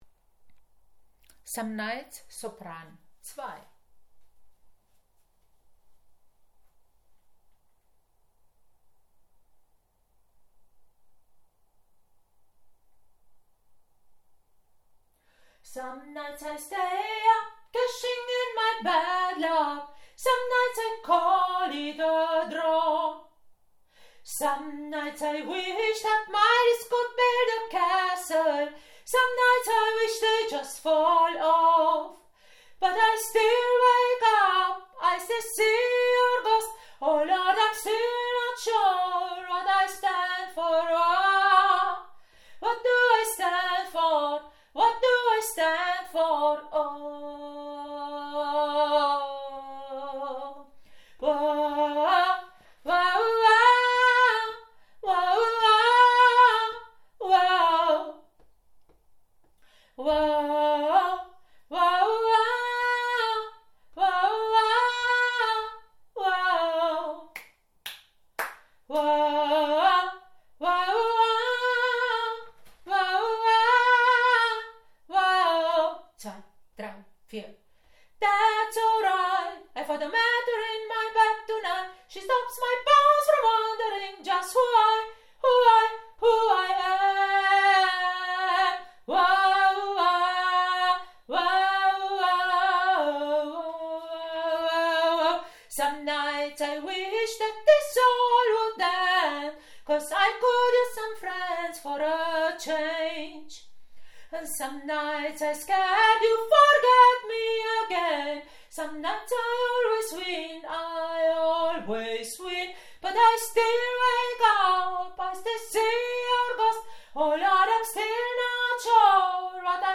02 - Sopran - ChorArt zwanzigelf - Page 2 | Der moderne Chor in Urbach
Some Nights Sopran 1